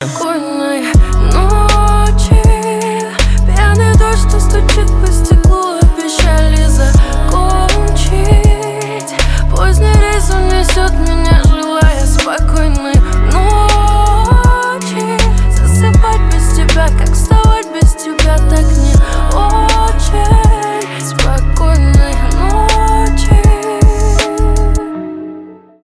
русский рэп , битовые , басы , пацанские , грустные